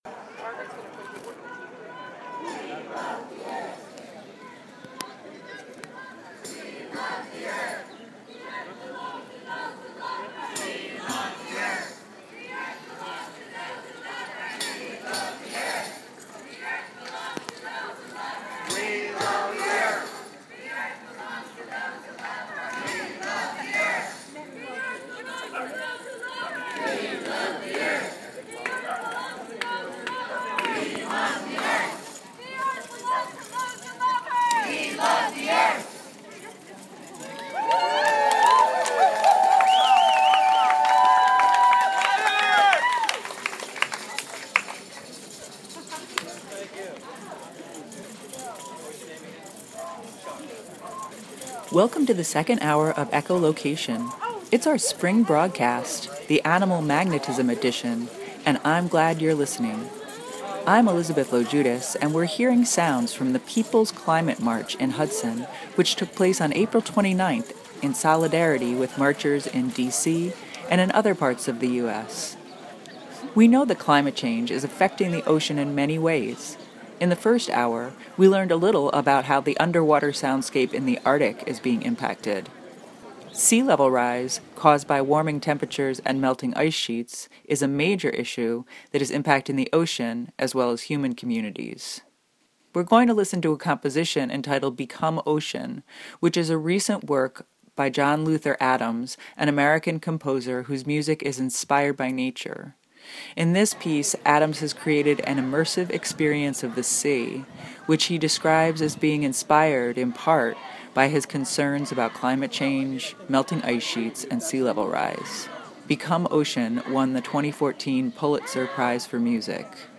Magnetic vibration ~ listen to the calls of amorous frogs that fill the night soundscape with song. Magnetic migration ~ investigate intrepid animal migrants that use the Earth’s magnetic field to find their way home. Magnetic levitation ~ hear the astounding story of frogs made to levitate using a giant magnetic field. The show will feature an Echolocator Profile, a report on People’s Climate March events in the Hudson Valley, and the simply stunning sounds of spring.